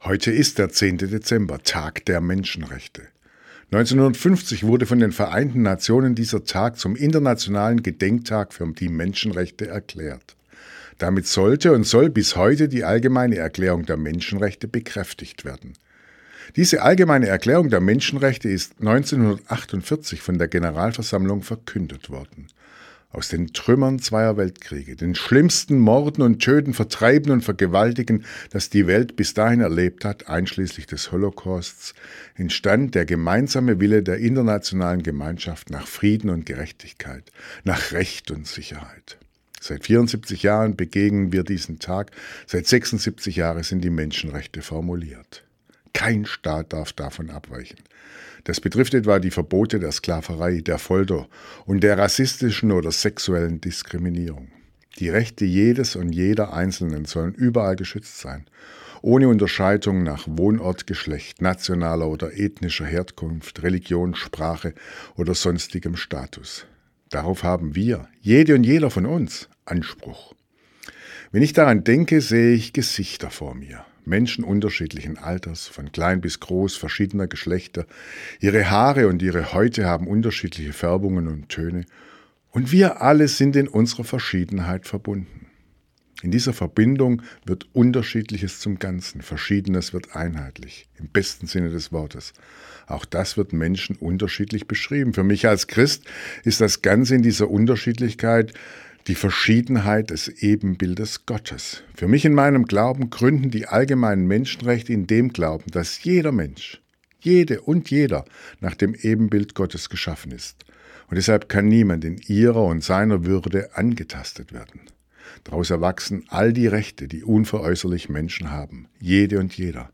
Radioandacht vom 10. Dezember – radio aktiv